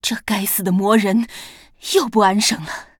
文件 文件历史 文件用途 全域文件用途 Cyrus_amb_02.ogg （Ogg Vorbis声音文件，长度3.0秒，99 kbps，文件大小：36 KB） 源地址:游戏语音 文件历史 点击某个日期/时间查看对应时刻的文件。